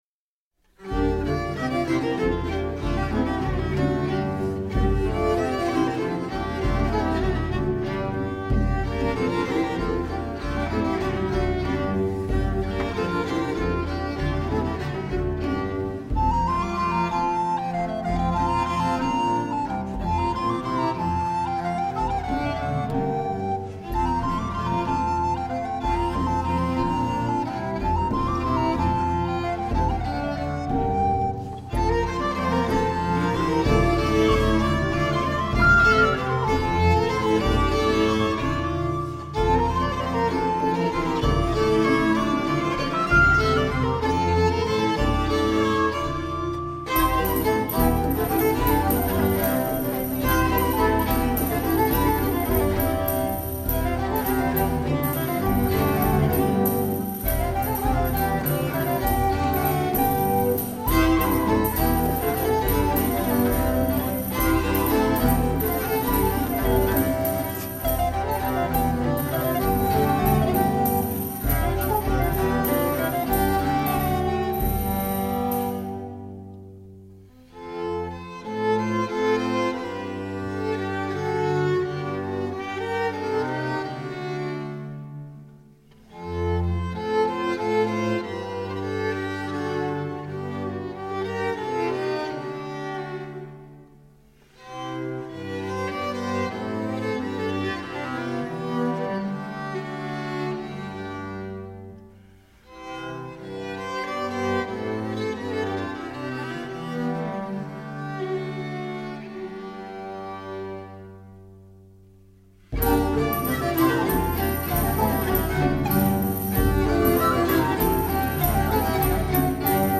Tourdion
~1450 - ~1580 (Renaissance)
Group: Dance